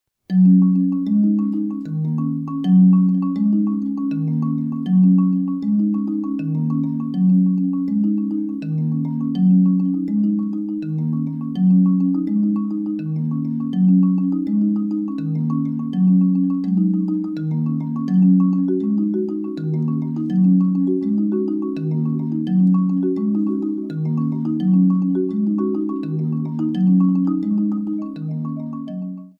marimbas, bass drum
accordion, synthesizer